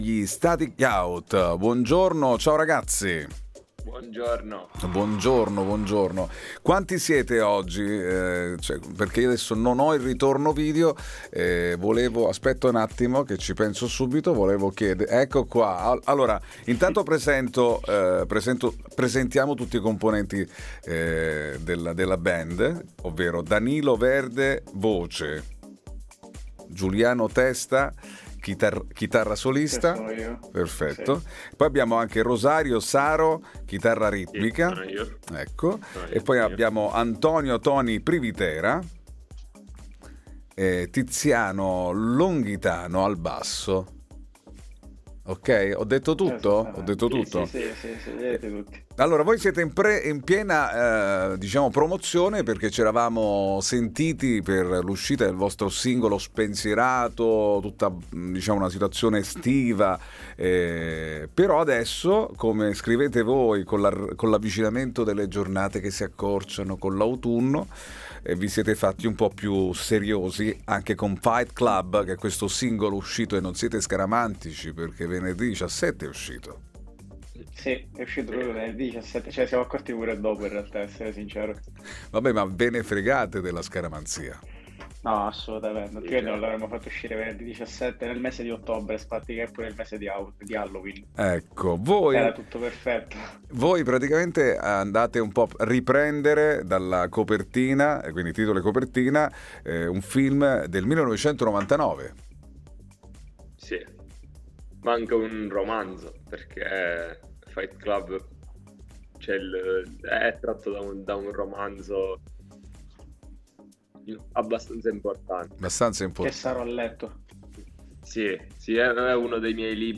INTERVISTA STATIC YOUTH- PRESENTANO “FIGHT CLUB”